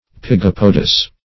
Pygopodous \Py*gop"o*dous\
pygopodous.mp3